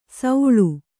♪ sauḷu